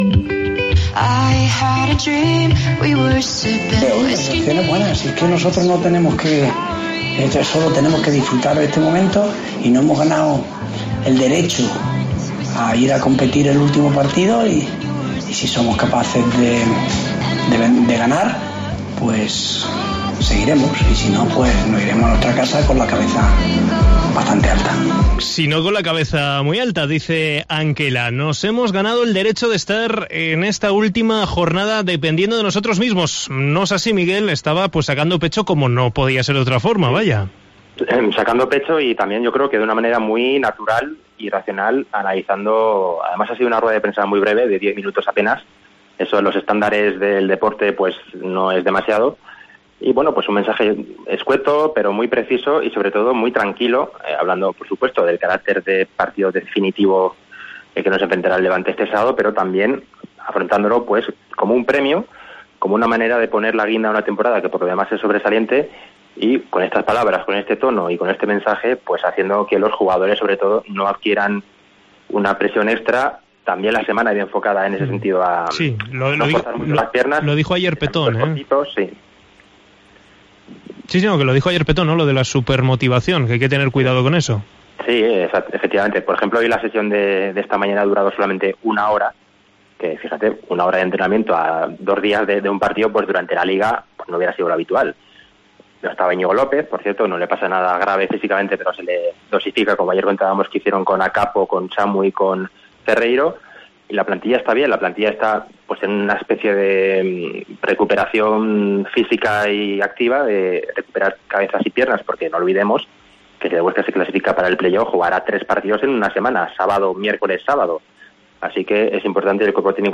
en sala de prensa